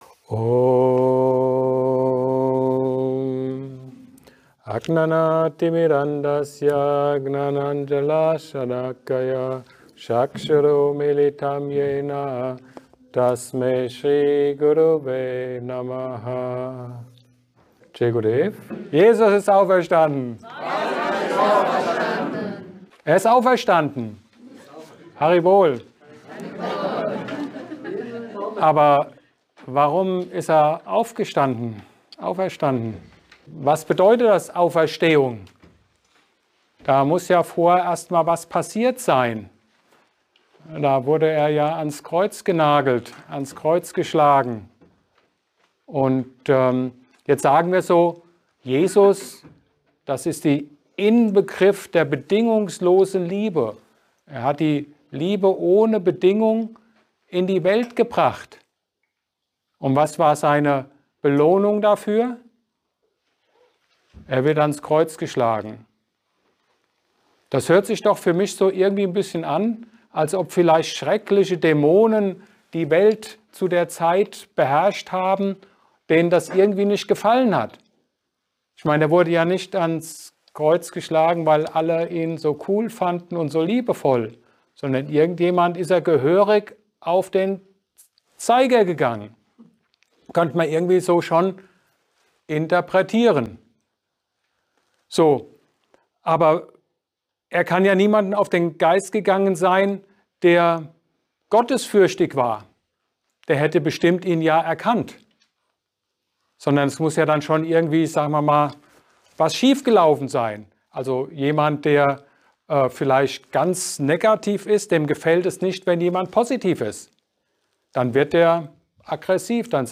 Ansprache zu Ostern 2024